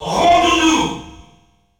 The announcer saying Jigglypuff's name in French releases of Super Smash Bros.
Jigglypuff_French_Announcer_SSB.wav